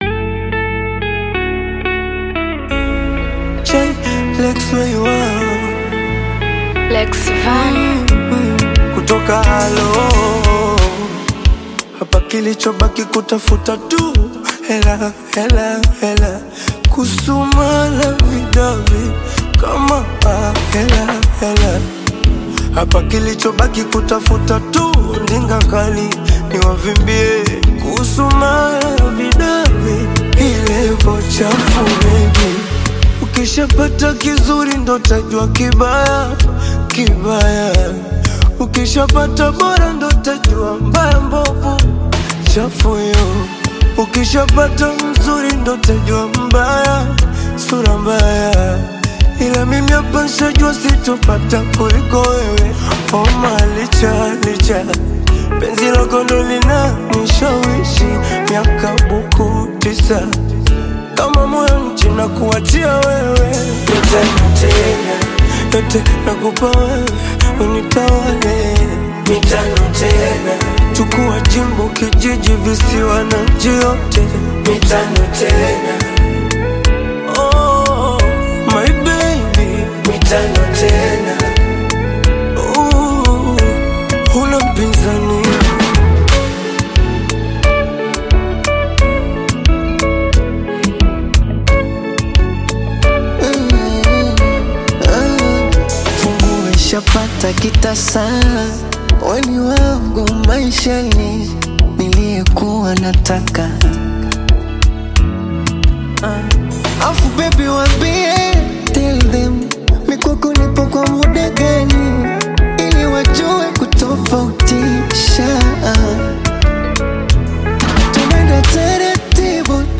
Bongo Fleva and Amapiano vibes
smooth vocals, street flavor, and infectious rhythm
a feel-good track